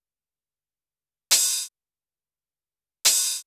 OP HH SD  -R.wav